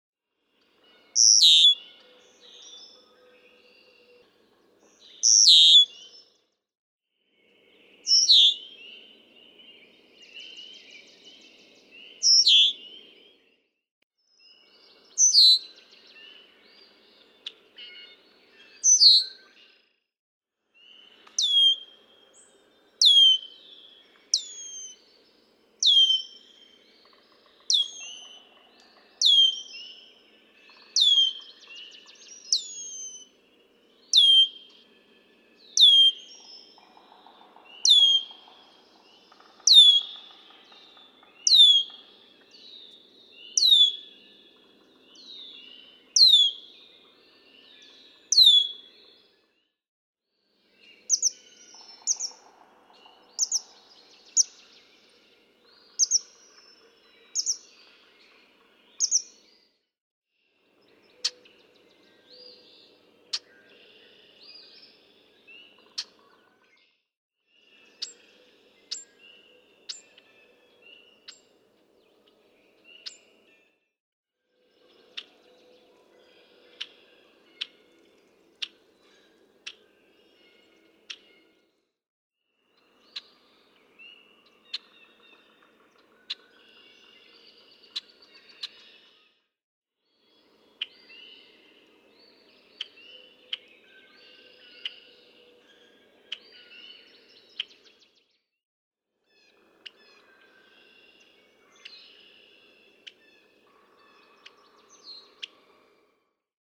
Red-winged blackbird
♫327. Michigan call dialect, excerpted from a long dawn sequence.
327_Red-winged_Blackbird.mp3